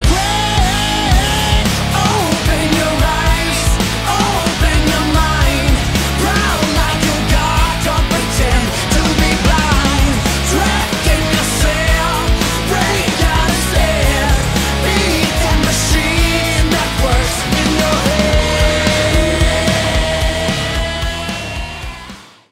громкие
мощные
Драйвовые
электрогитара
nu metal
Обновленная версия культового рок рингтона